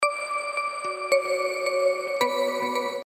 • Качество: 320, Stereo
мелодичные
без слов
колокольчики
Нежное мелодичное уведомление на сообщения